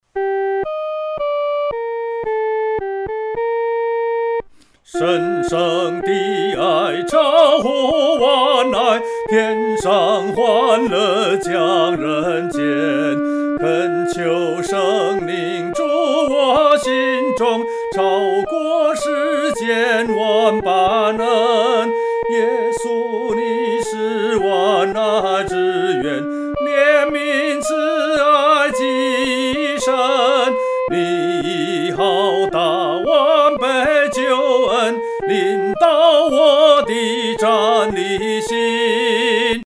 独唱（第一声）